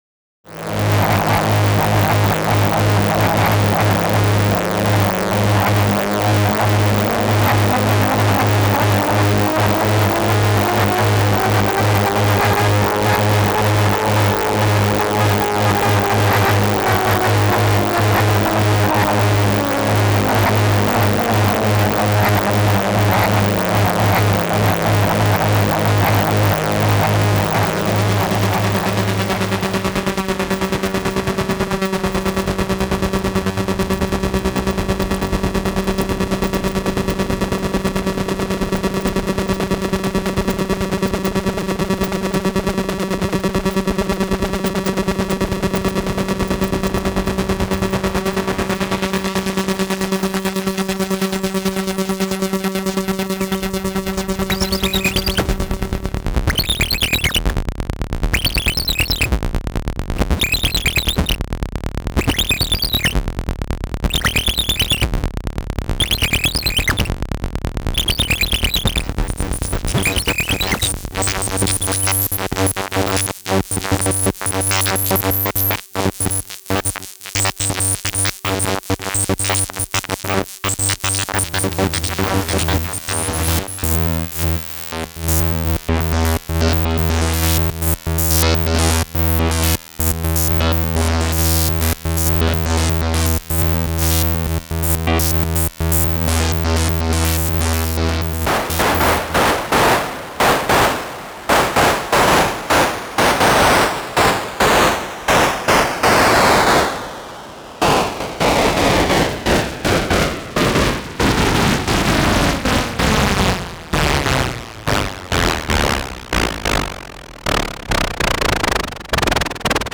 demo 2: noise and random fx
input: various vcos and doepfer a-118 noise.
cv: doepfer a-118 noise, mfb dual lfo (random).